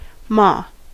Ääntäminen
IPA : /mɑː/